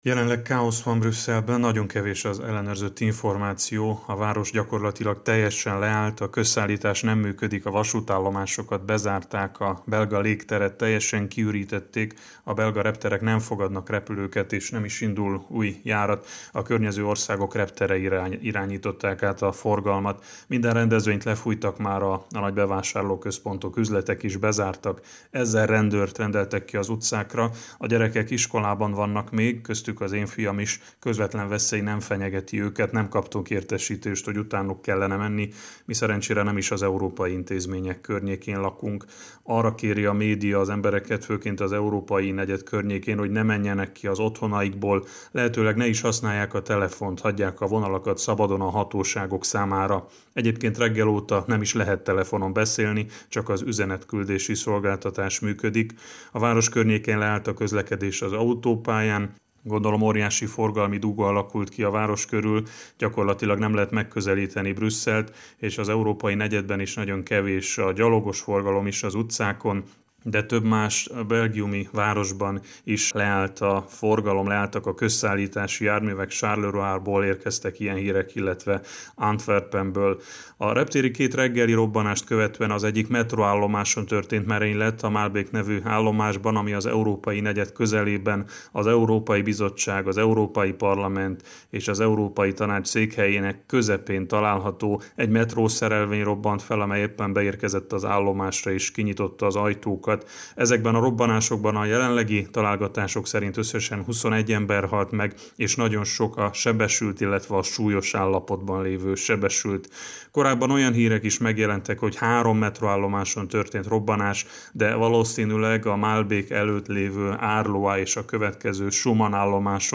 Helyszíni jelentés Brüsszelből
Brüsszelben van volt munkatársunk, Vincze Loránt, az RMDSZ külügyi titkára, aki a következő tudósítást juttatta el interneten keresztül szerkesztőségünkbe, ugyanis a telefonhálózat annyira terhelt Brüsszelben, hogy képtelenség volt közvetlen összeköttetést létesíteni vele. Vincze Lorántot hallják.